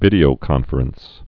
(vĭdē-ō-kŏnfər-əns, -frəns)